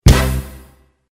เสียงตลกตบมุก 6
หมวดหมู่: เสียงมีมไทย
tieng-trong-joke-6-th-www_tiengdong_com.mp3